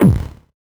Index of /musicradar/8-bit-bonanza-samples/VocoBit Hits
CS_VocoBitB_Hit-17.wav